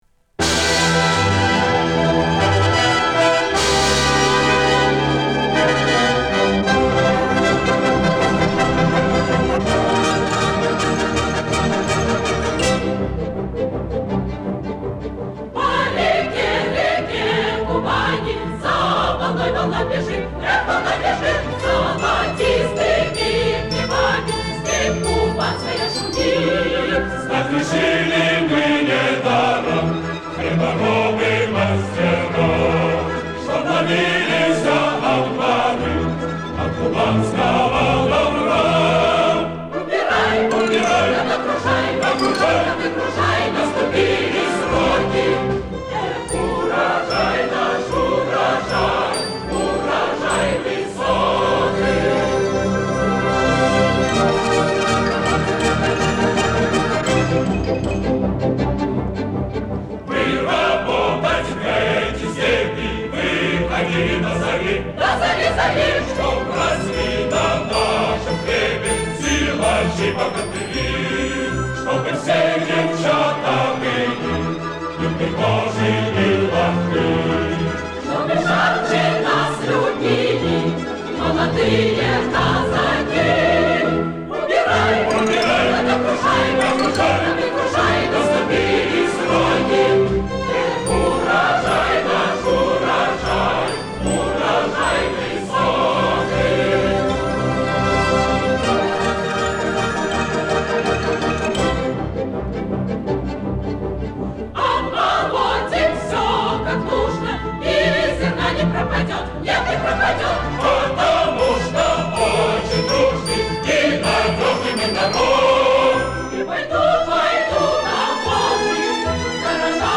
Версия в исполнении хора.